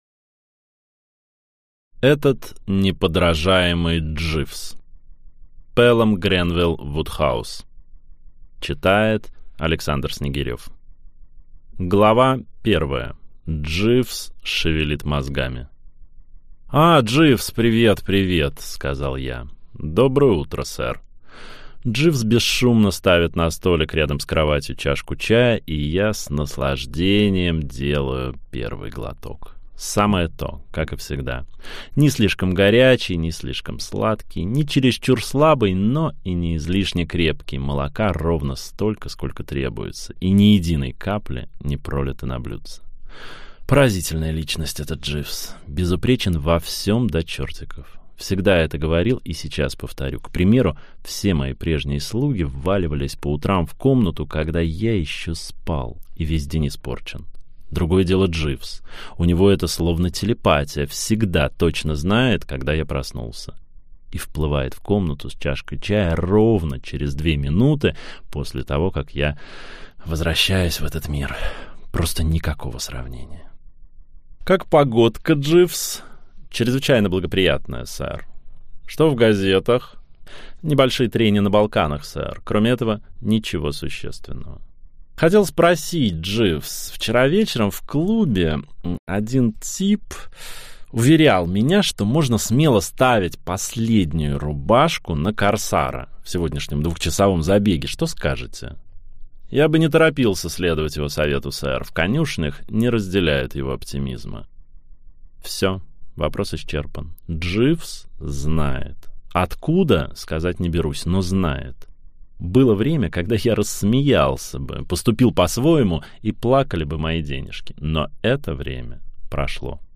Аудиокнига Этот неподражаемый Дживс | Библиотека аудиокниг